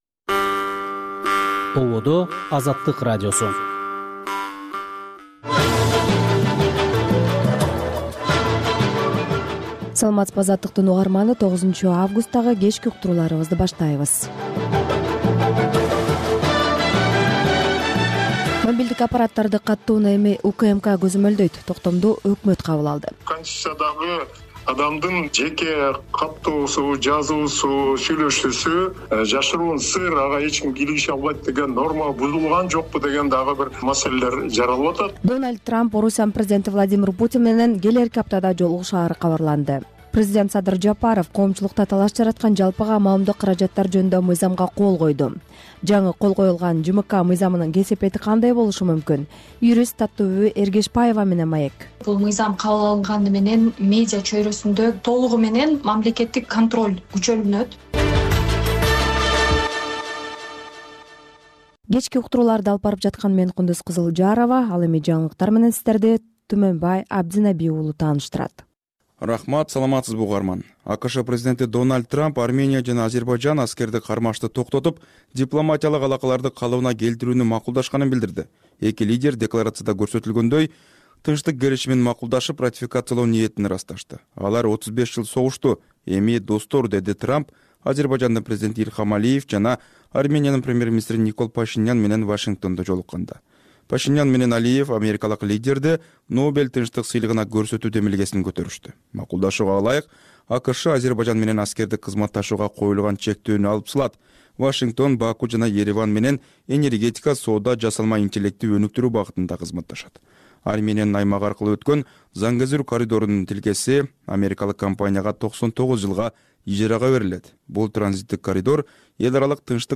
Бул үналгы берүү ар күнү Бишкек убакыты боюнча саат 18:00ден 18:30га чейин обого түз чыгат.